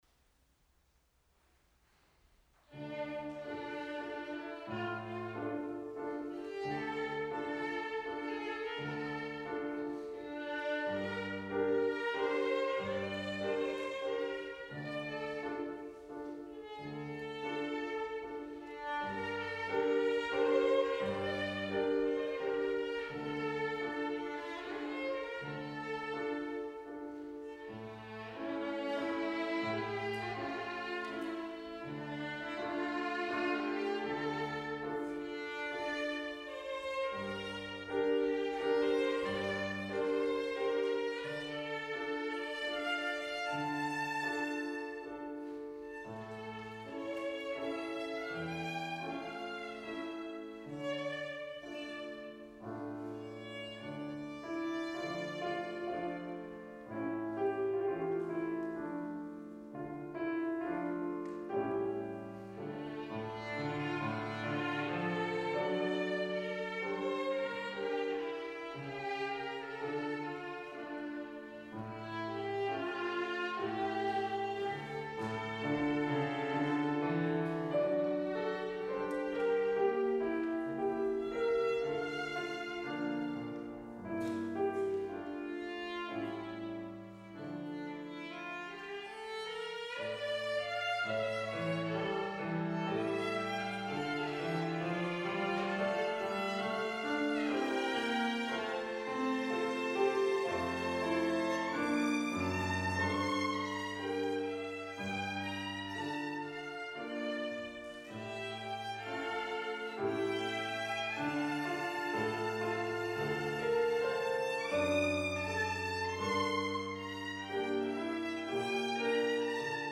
Back to Works Inst: Violin and Piano Duration
Live performance audio below